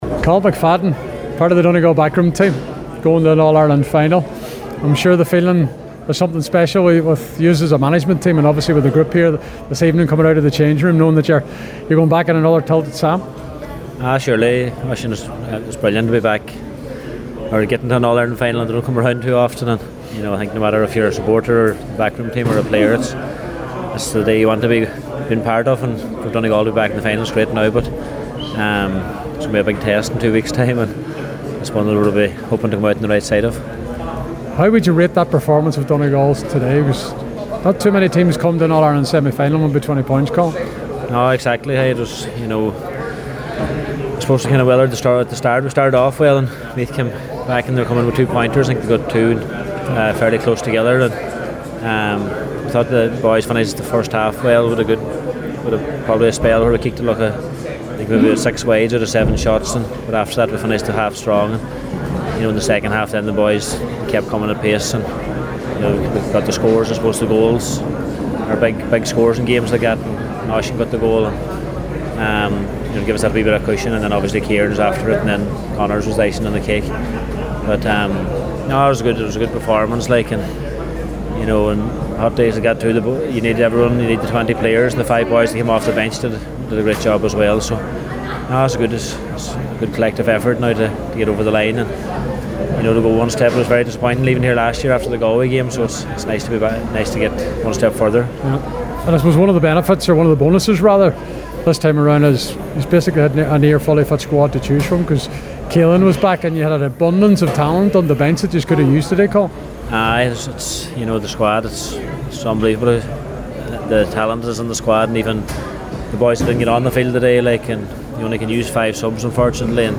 after full time at GAA HQ today…